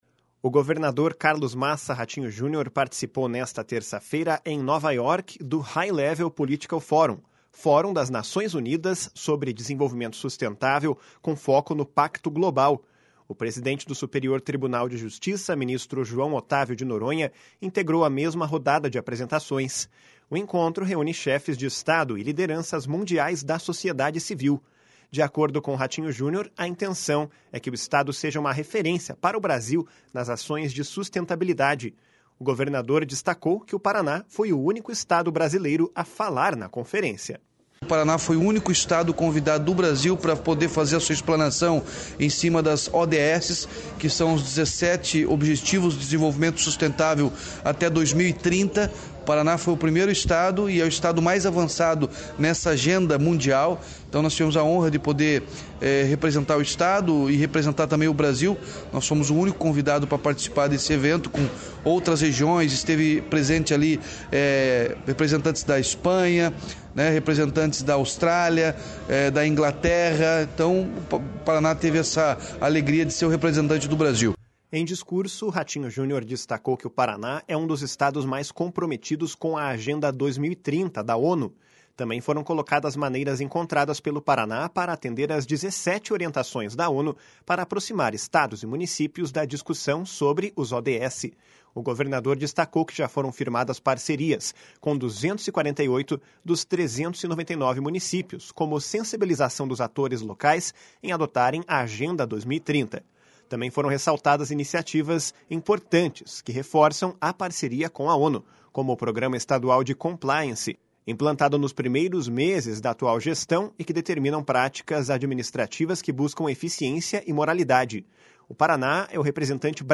// SONORA RATINHO JUNIOR //
Repórter